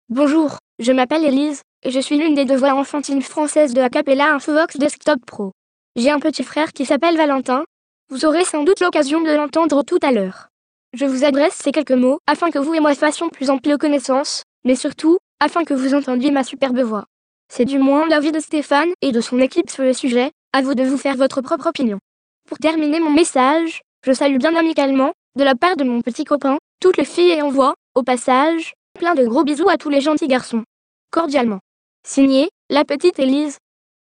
Texte de démonstration lu par Élise, voix enfantine française d'Acapela Infovox Desktop Pro
Écouter la démonstration d'Élise, voix enfantine française d'Acapela Infovox Desktop Pro